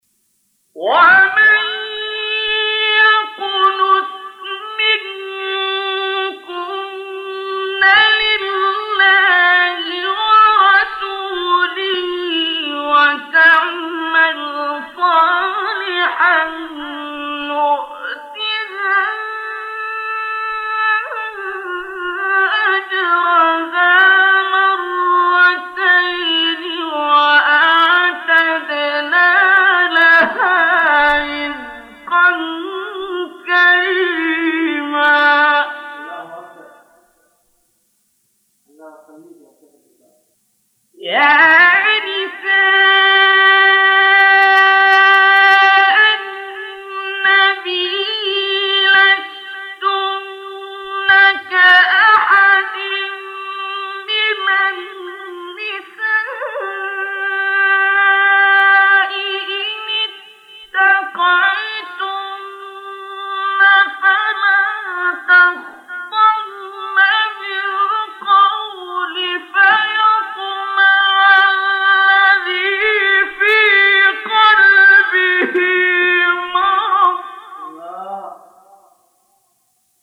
گروه فعالیت‌های قرآنی: فرازهای صوتی دلنشین با صوت قاریان برجسته مصری ارائه می‌شود.